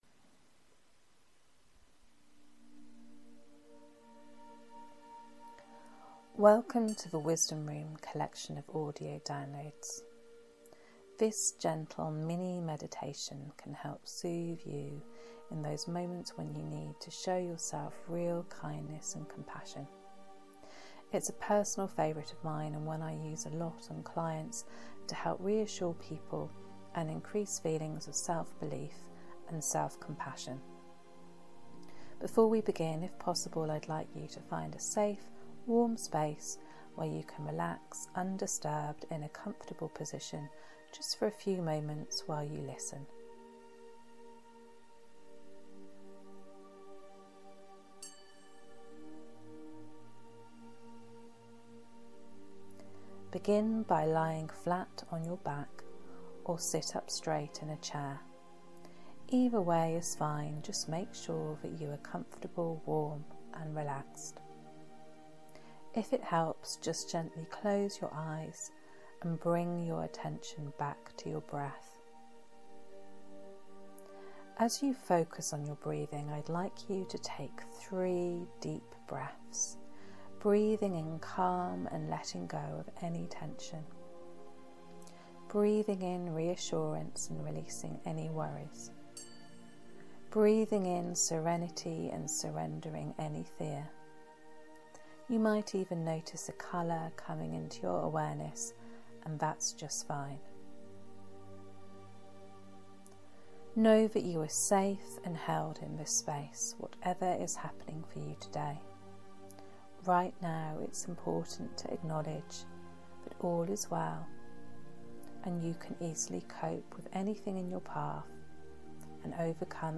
SELF-SOOTHE-Mini-Meditation-6-mins.mp3